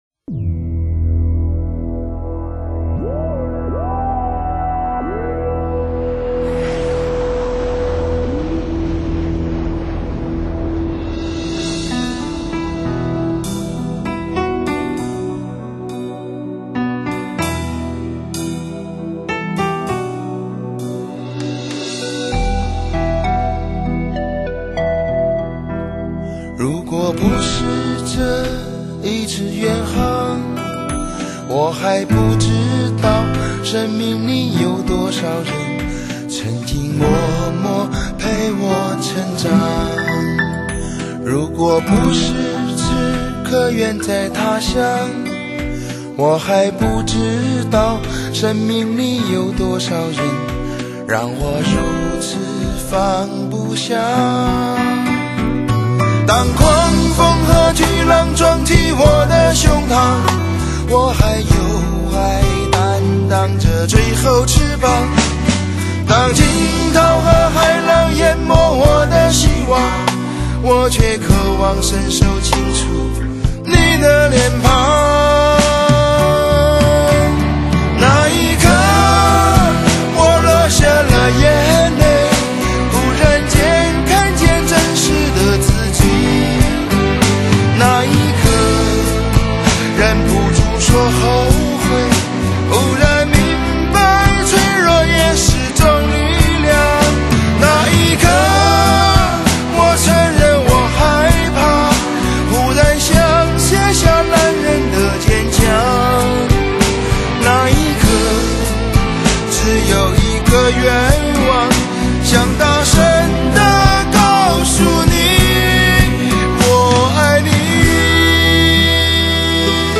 不论是声音质量还是混声效果都具有很高水平。